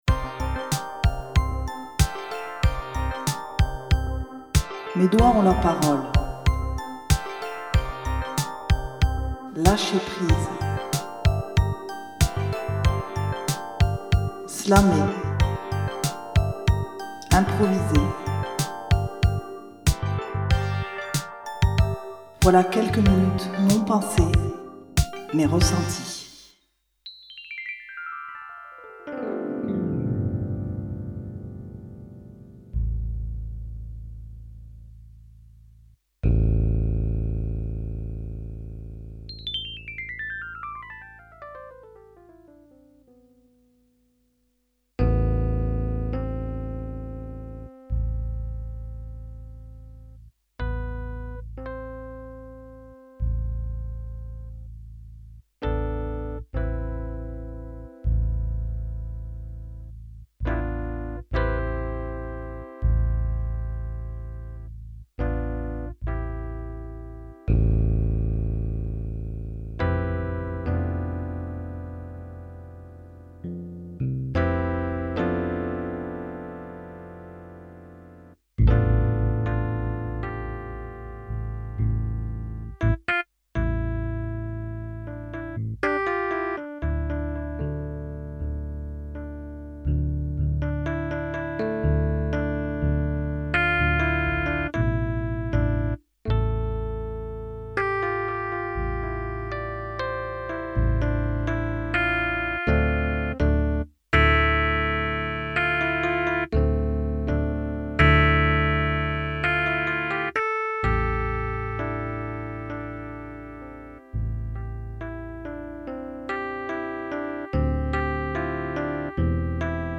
enregistré au studio Radio Muge.